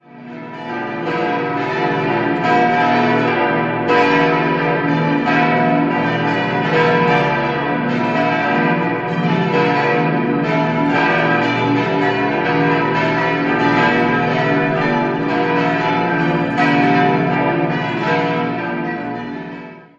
Jahrhundert erfolgte eine Barockisierung, im Zuge derer auch die Fresken von Franz Anton Zeiller geschaffen wurden. 7-stimmiges ausgefülltes und erweitertes H-Moll-Geläute: h°-d'-e'-fis'-a'-h'-d'' Alle Glocken wurden 1956 von der Gießerei Grassmayr in Innsbruck gegossen.